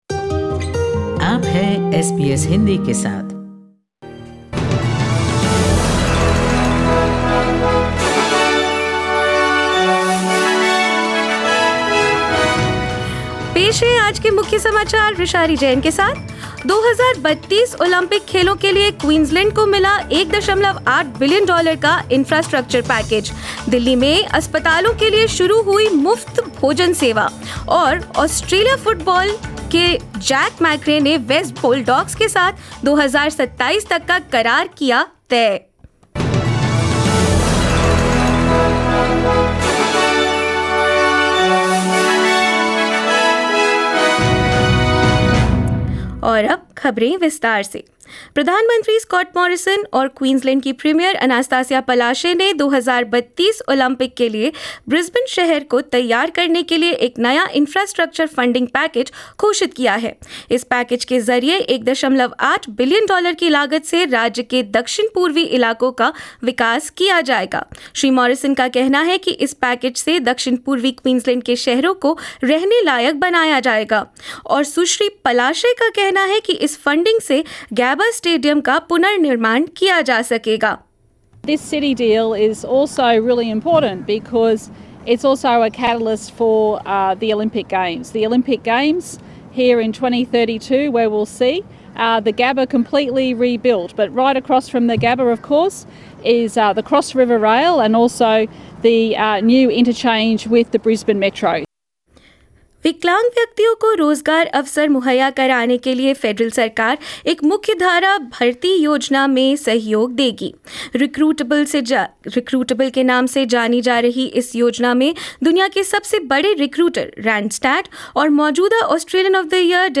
SBS Hindi News 21 March 2022: Australia to invest $1.8billion in infrastructure for 2032 Olympics